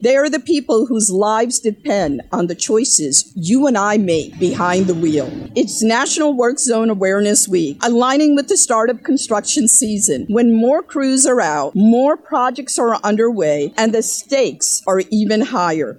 More than 48,000 citations have been issued in the first two months of the new tiered fine structure in the state for work zone speed violations in Maryland. As part of a ceremony for National Work Zone Safety Week, Lt. Governor Aruna Miller said paying attention to highway workers and work zones is critical year-round…